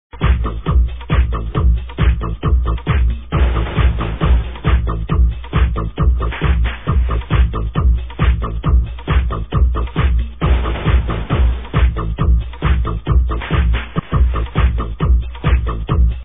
SICK BEAT..neeed help!!!!!